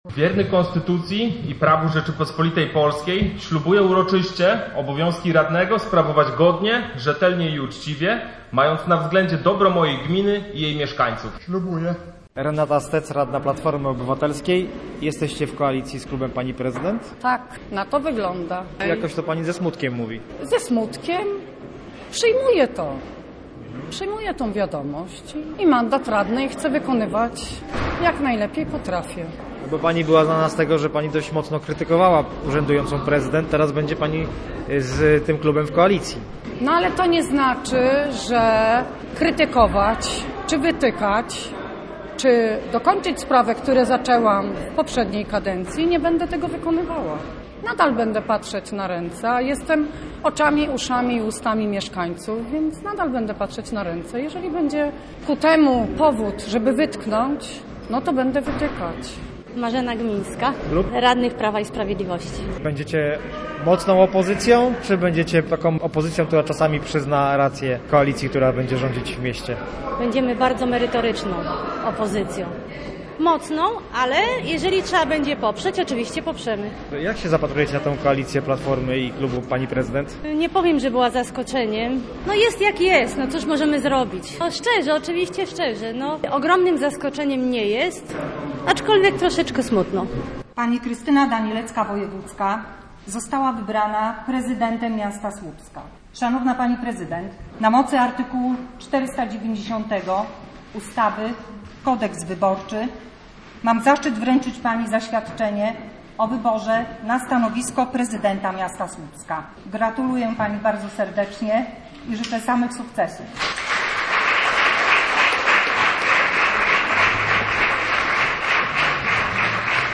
Dziś na pierwszej sesji nowej rady miasta złożyła ślubowanie.